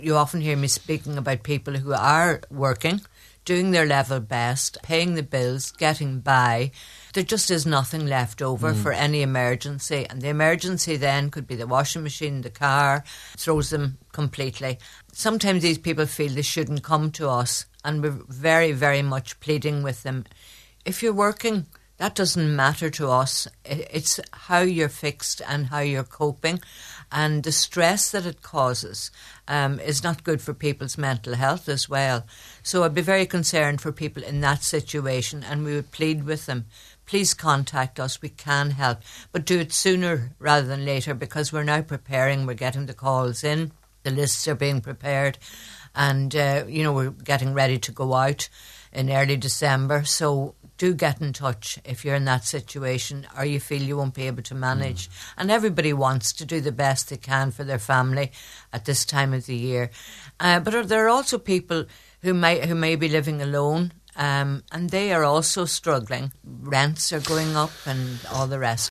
Speaking on today’s Nine til Noon Show, she said many are barely surviving due to rising food and energy costs.